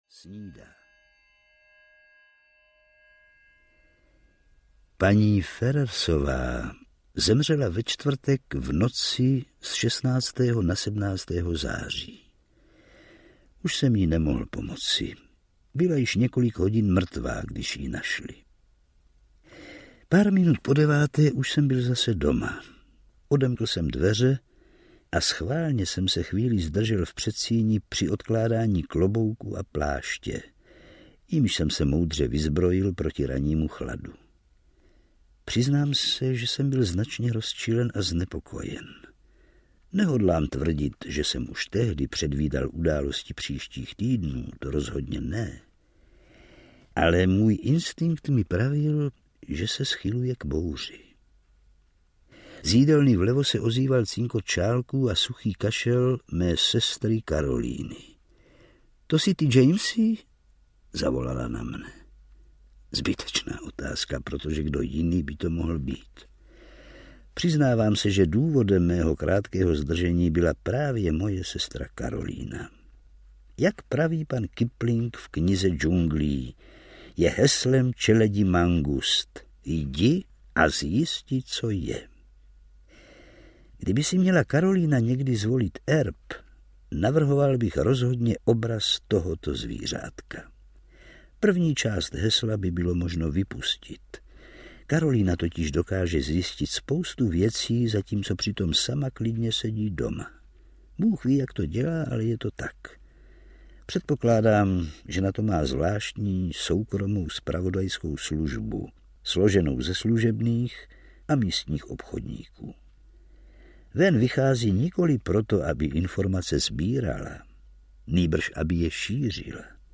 Vražda Rogera Ackroyda audiokniha
Ukázka z knihy
vrazda-rogera-ackroyda-audiokniha